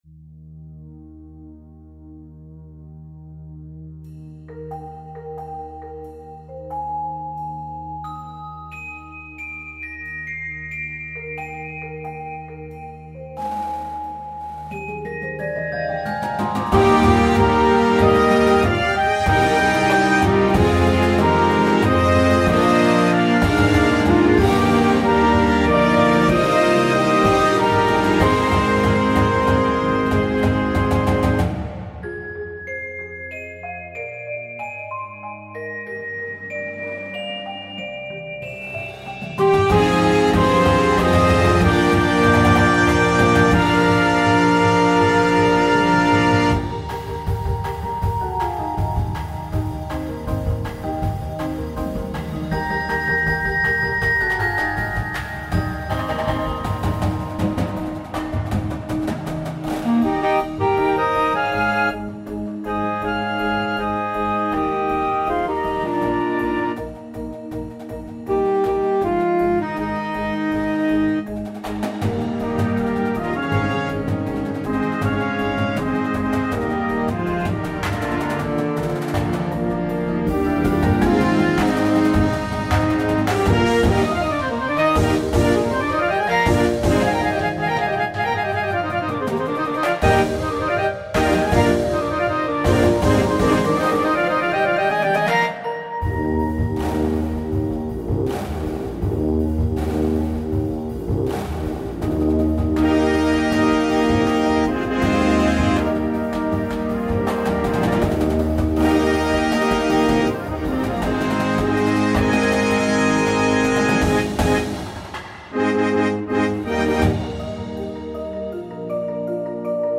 the show builds a warm yet vibrant soundscape.